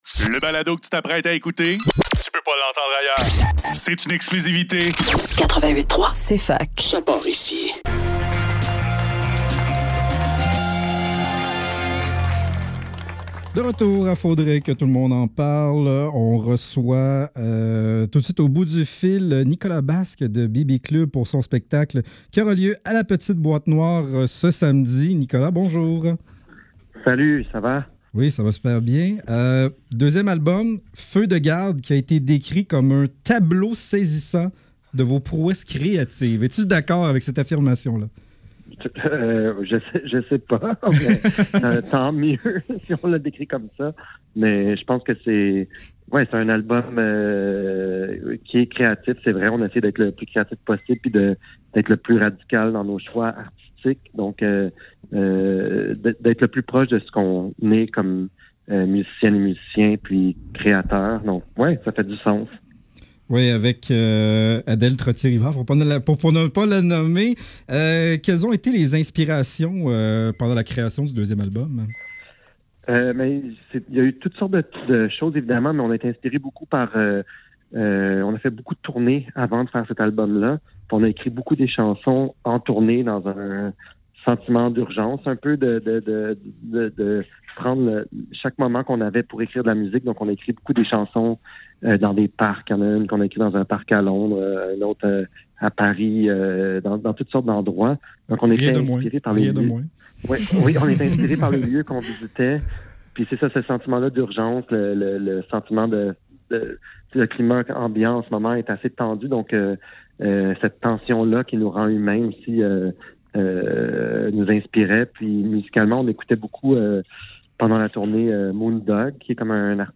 Faudrait que tout l'monde en parle - Entrevue avec Bibi Club - 1 octobre 2024